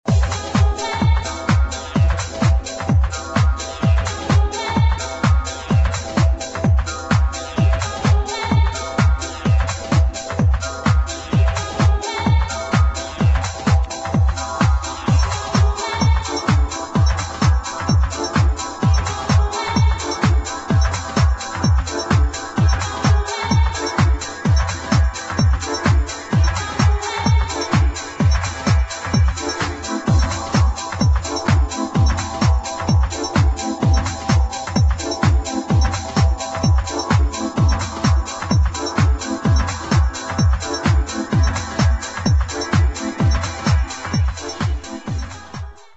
HOUSE | DISCO